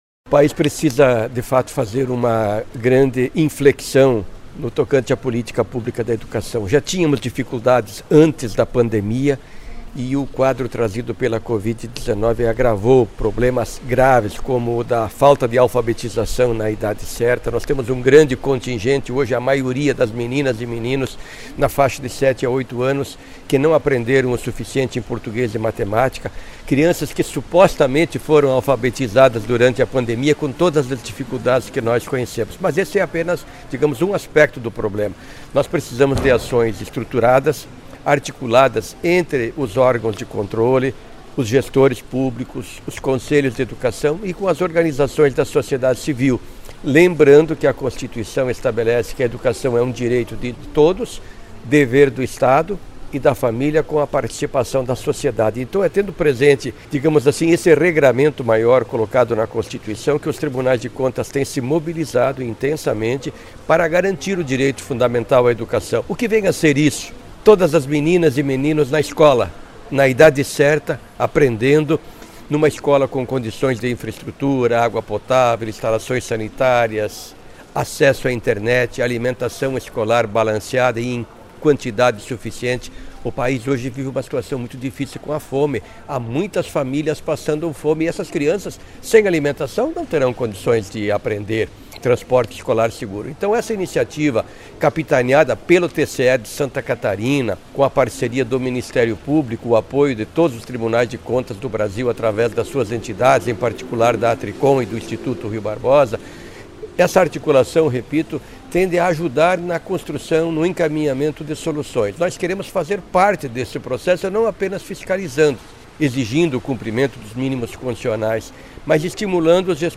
IV Sined e III Encontro de Promotores e Promotoras de Justiça da Educação - áudios dos participantes
Solenidade de abertura
Cezar Miola – presidente da Atricon (